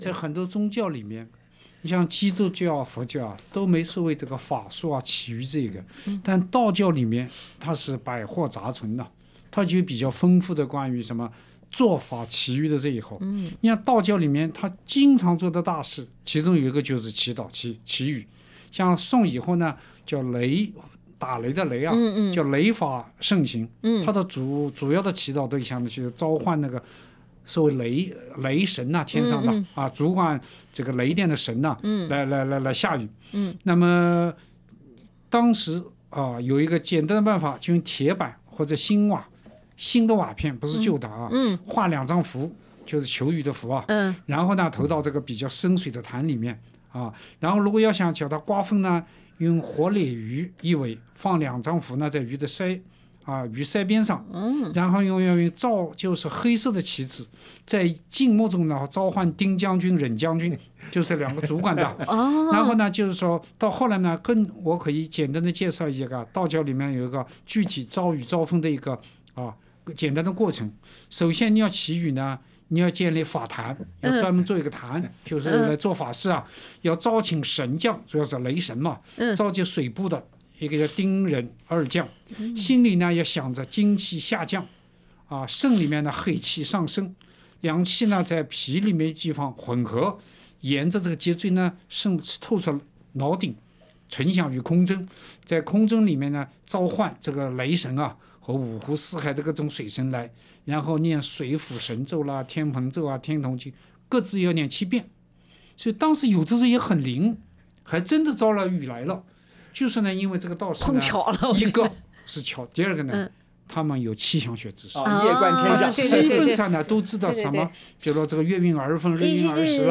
对话中文化苦丁茶三人组对当下存在的一些粮食浪费现象引经据典地予以了批评。
SBS电台《文化苦丁茶》每周五早上澳洲东部时间早上8:15播出，每周日早上8:15重播。